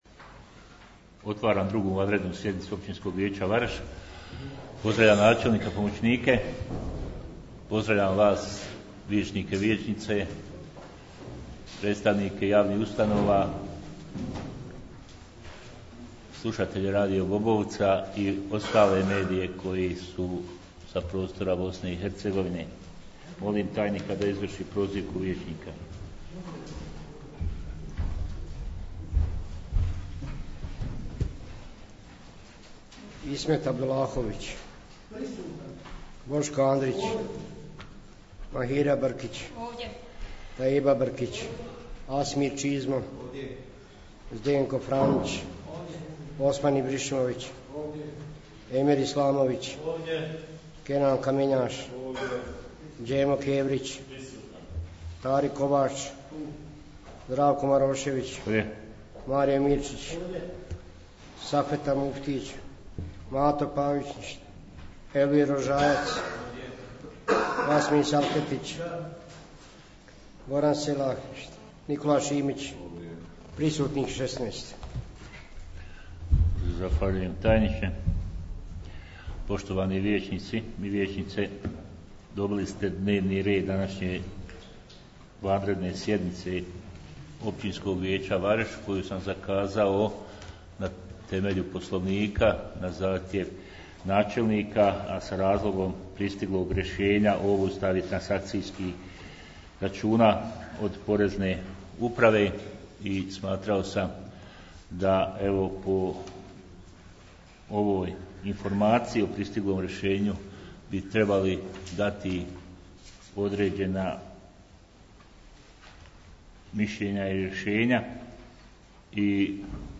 2. izvanredna sjednica Općinskog vijeća